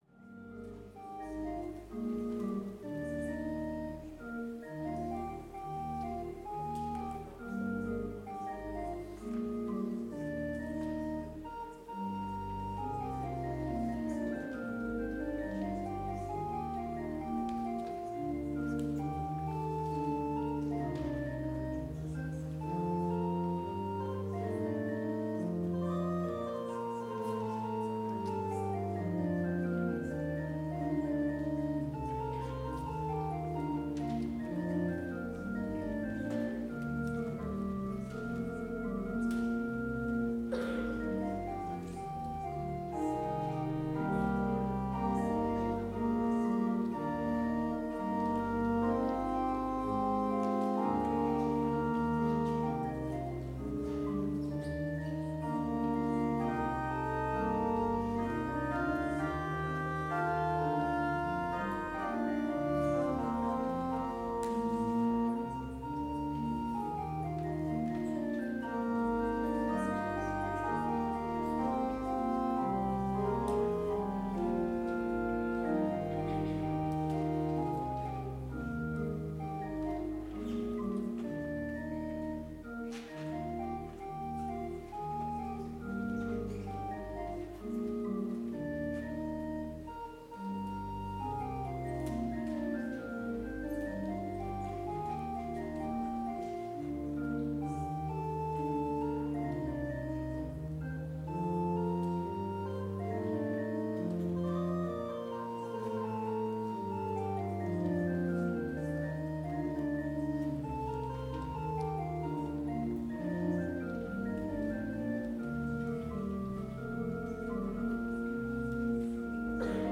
Complete service audio for Vespers - Wednesday, November 20, 2024